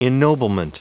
Prononciation du mot ennoblement en anglais (fichier audio)
Prononciation du mot : ennoblement